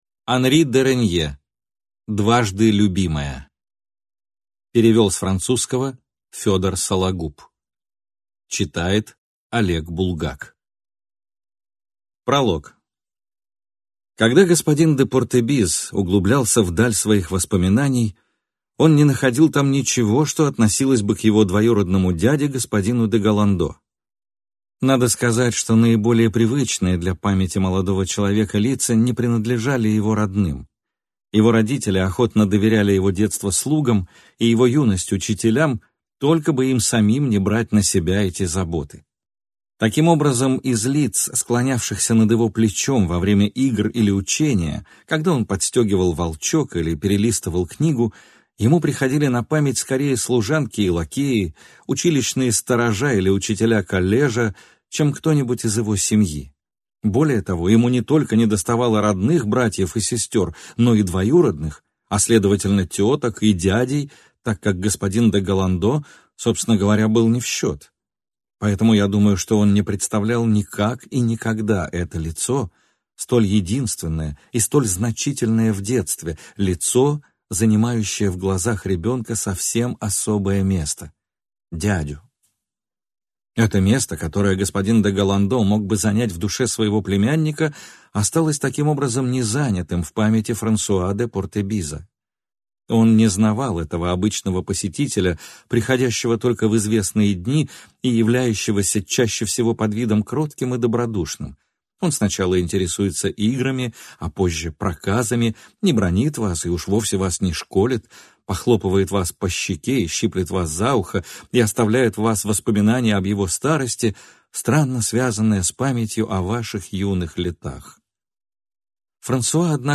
Аудиокнига Дважды любимая | Библиотека аудиокниг
Прослушать и бесплатно скачать фрагмент аудиокниги